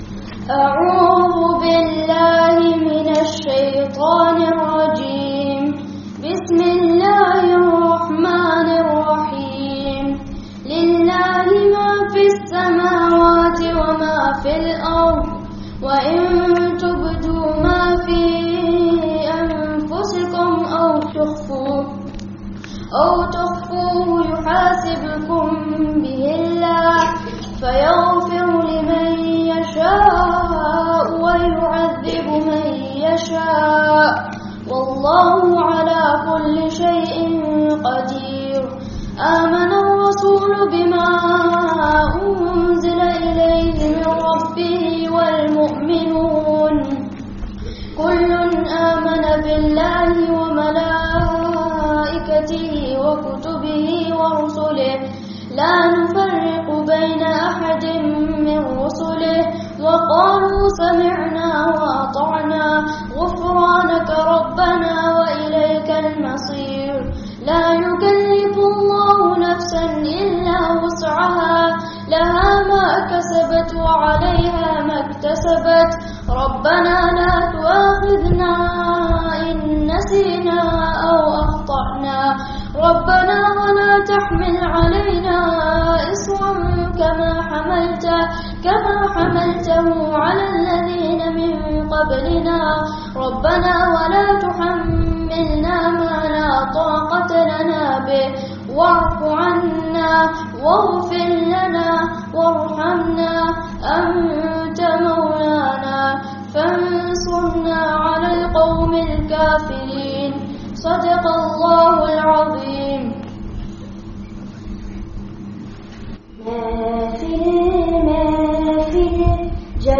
Allāh's Kindness (A Beautiful Message for Children) [Annual Jalsah] (Madrasah Islamiyah, Leicester 08/08/19)